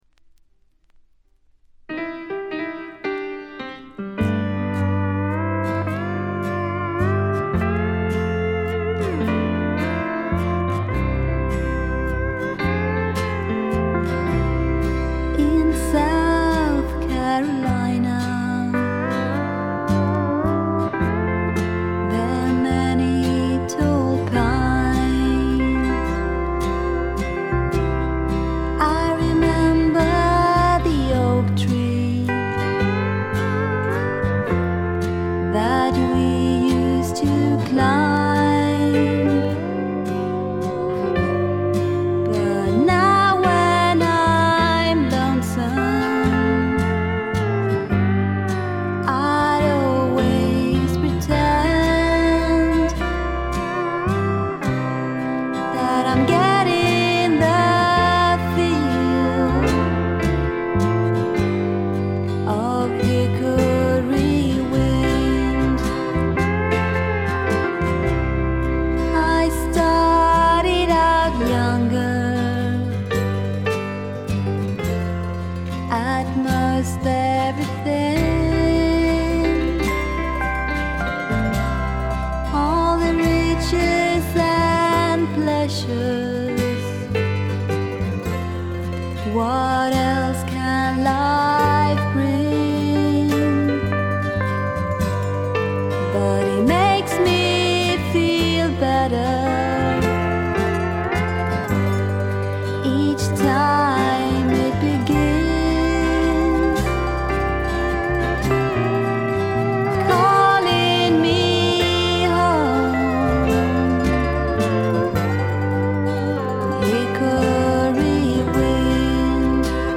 静音部での軽いチリプチ程度。
ちょっと舌足らずなクリスタルな甘えん坊ヴォイスが実に魅力的でノックアウト必至。
試聴曲は現品からの取り込み音源です。